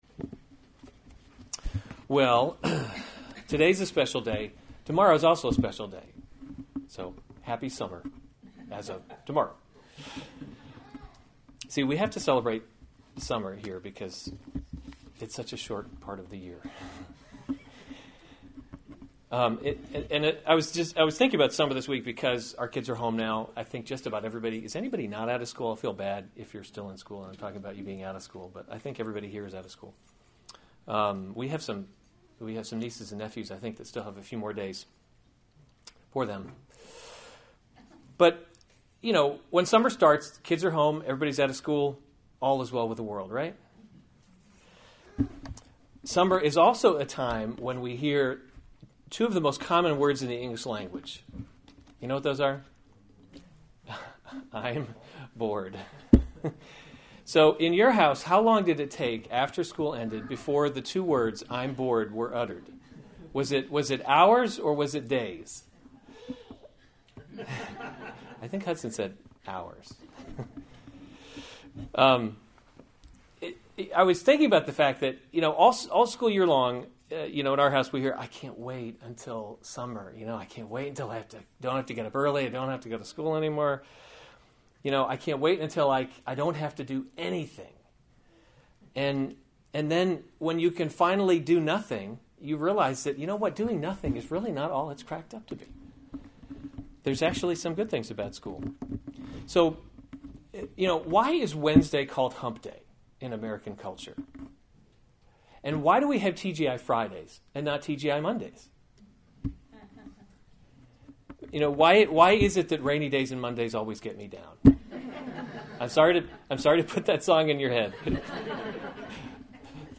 June 18, 2016 2 Thessalonians – The Christian Hope series Weekly Sunday Service Save/Download this sermon 2 Thessalonians 3:6-12 Other sermons from 2 Thessalonians Warning Against Idleness 6 Now we command […]